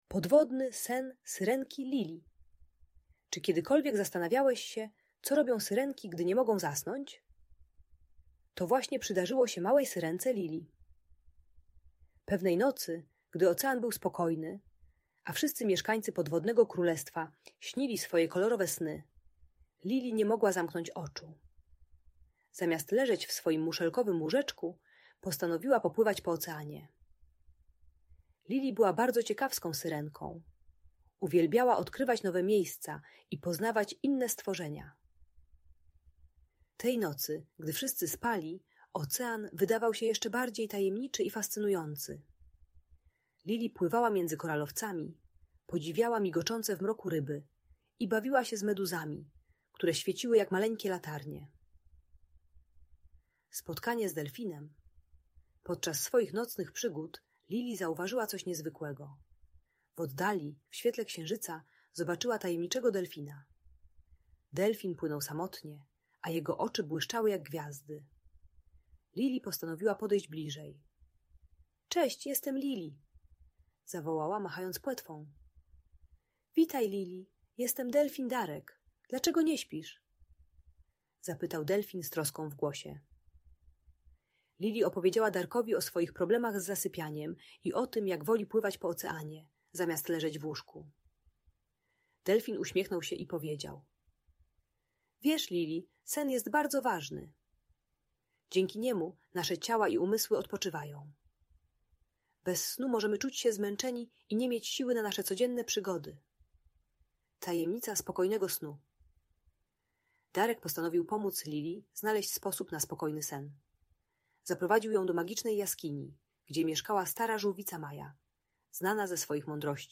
Podwodny Sen Syrenki Lili - Audiobajka dla dzieci